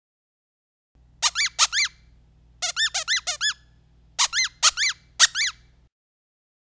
Squeaker Pillow Double-Voice - Small (12 Pack) - Trick
Double-voice squeakers make a sound both when they are pressed and when they are released.